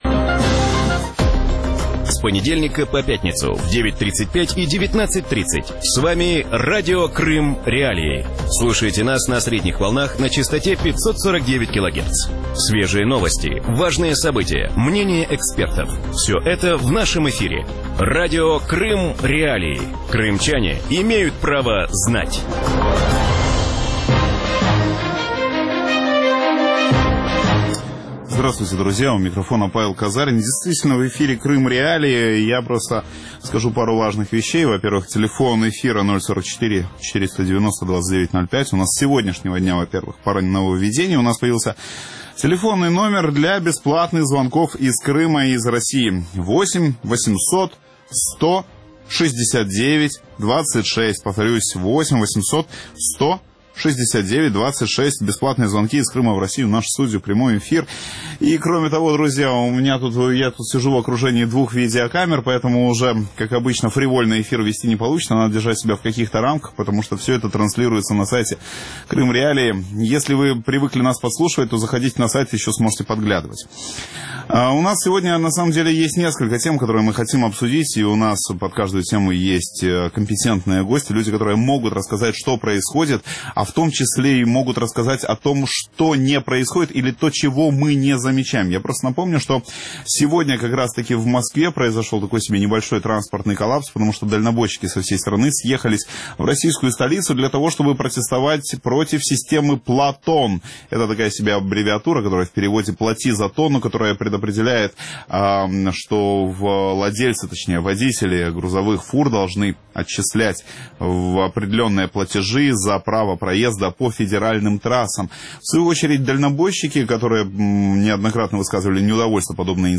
В вечернем эфире Радио Крым.Реалии говорят о главных событиях, которые произошли в жизни Российской Федерации и Крыма за неделю. Гости в студии – российский политолог Дмитрий Орешкин, депутат Государственной Думы РФ Дмитрий Гудков.